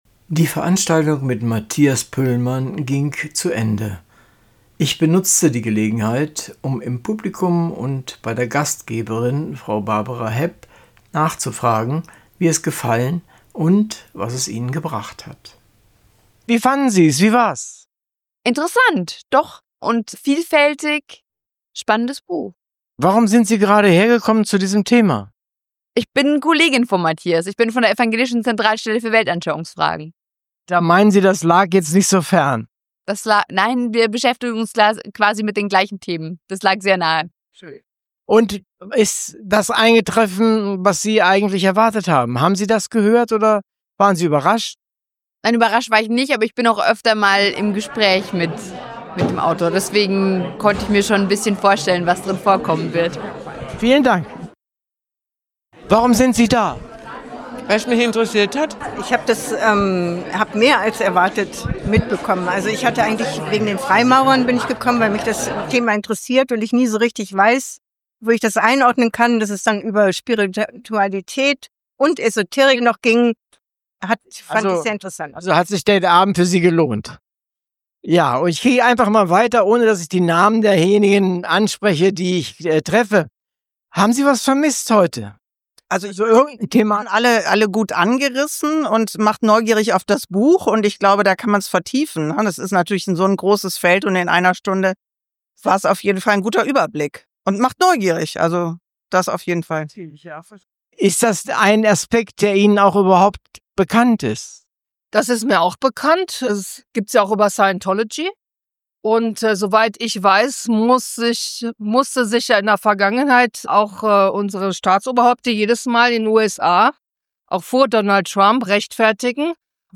Interviews am Rande der Veranstaltung(Hördauer ca. 12 min)